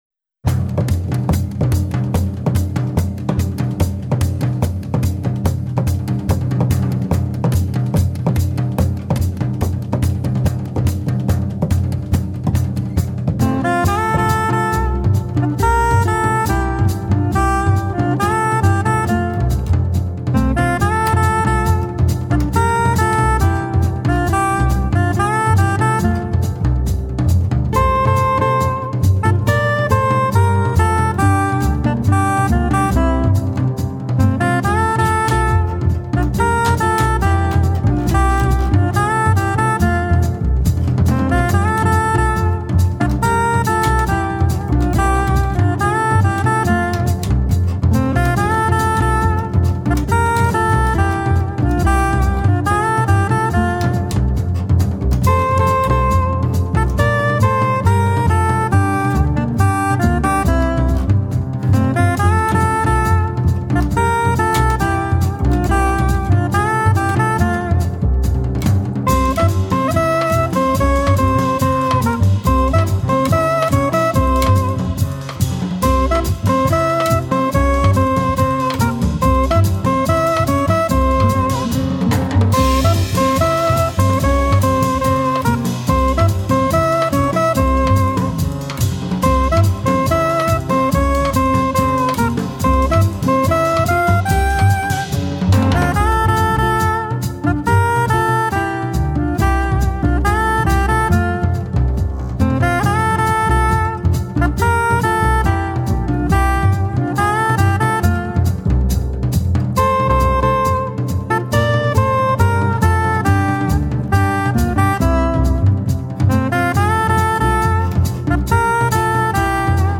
sax
chitarra classica
contrabbasso
batteria